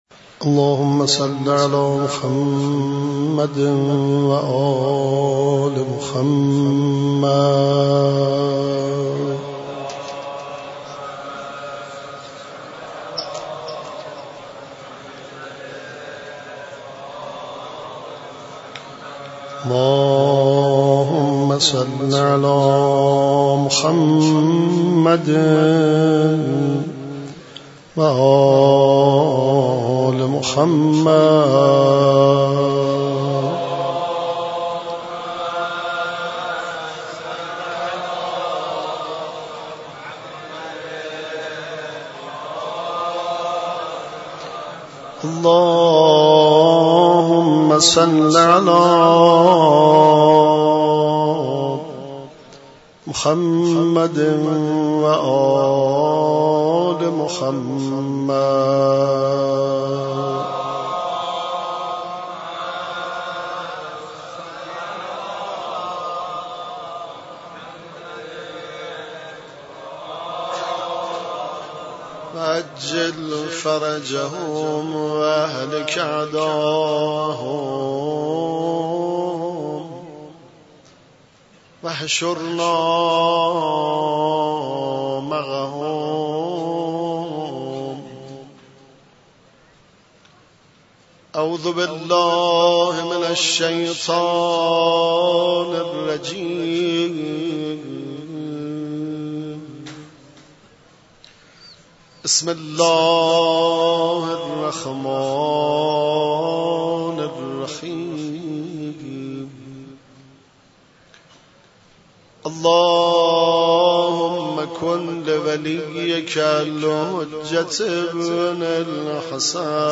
مراسم شب 19 , 21 ماه رمضان
در مسجد شهدا برگزار گردید
قرائت مناجات شعبانیه ، روضه امام علی (علیه السلام)